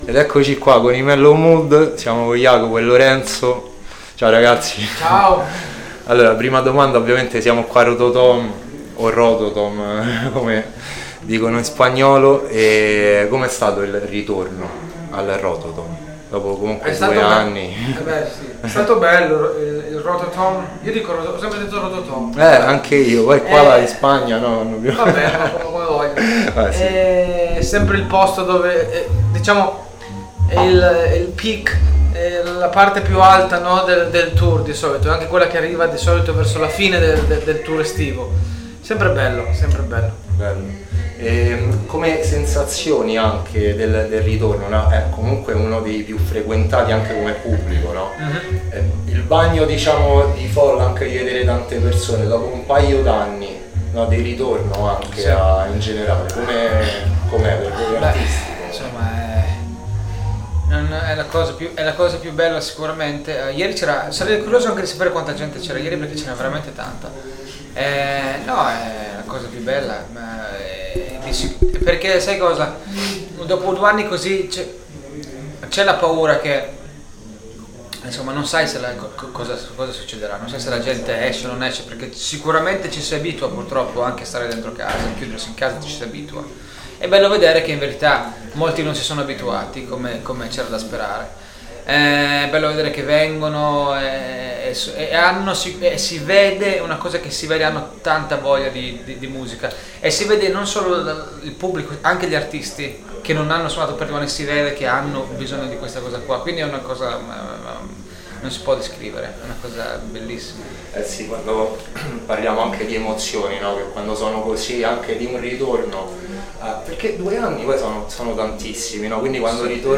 ARTISTA A LA VISTA | INTERVISTA MELLOW MOOD LIVE AL ROTOTOM SUNSPLASH | Radio Città Aperta
Artista-a-la-Vista-Intervista-Mellow-Mood.mp3